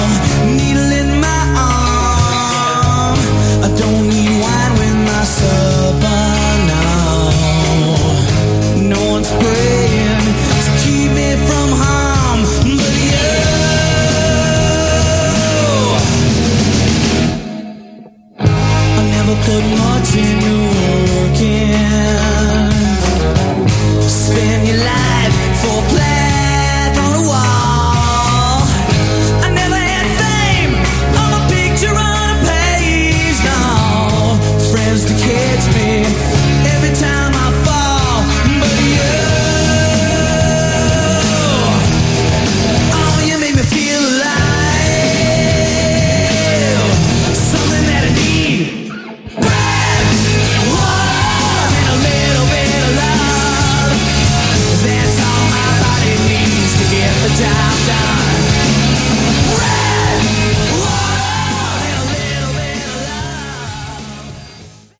Category: Hard Rock
lead and backing vocals
bass, backing vocals
lead and rhythm guitar, backing vocals
drums, backing vocals